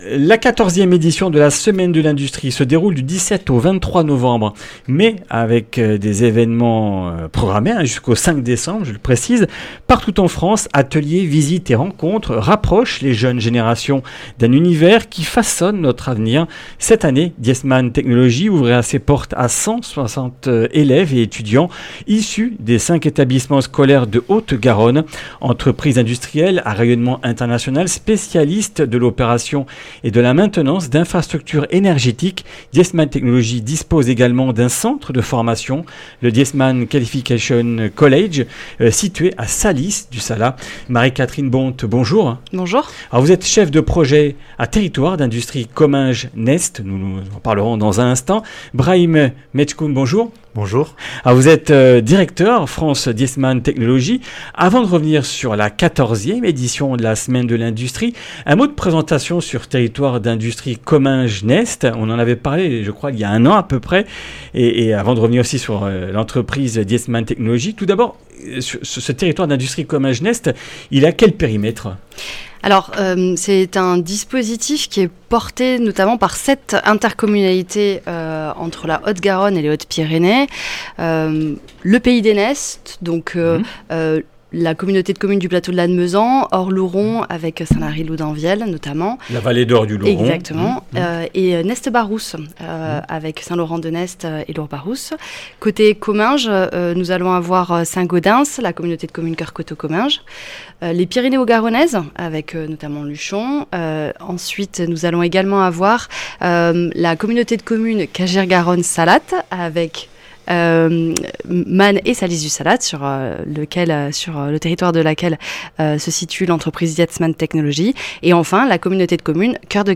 Comminges Interviews du 17 nov.